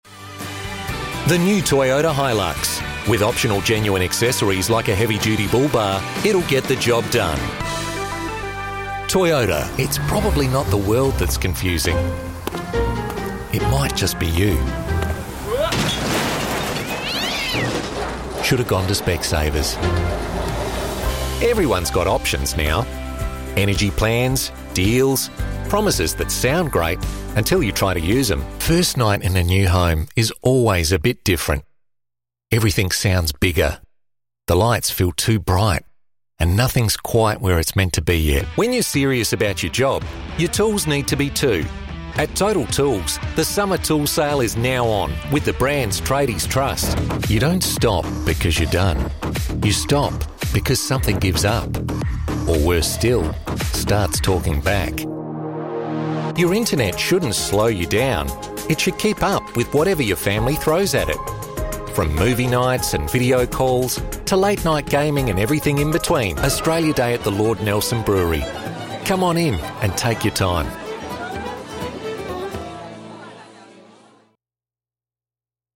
Australian Voice Over Artists
Male Voice Over Artists